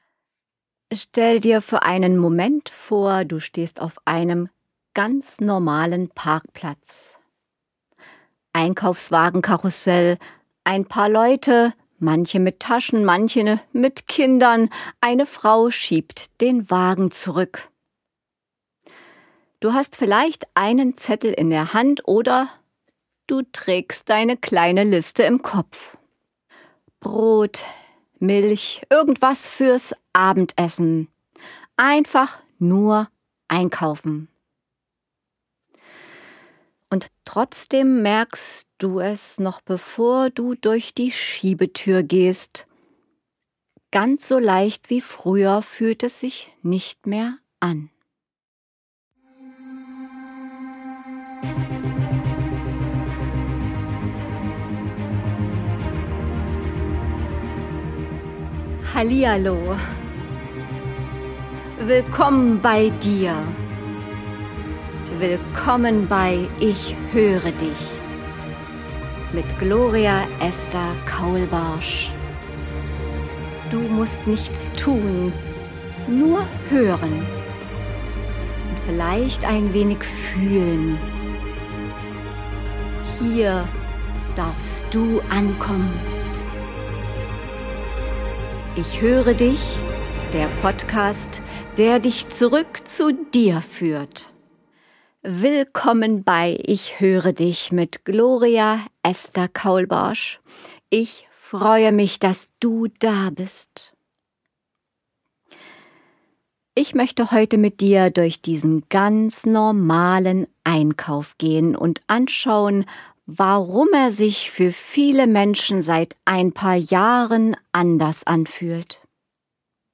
Aufgenommen im Greve Studio Berlin.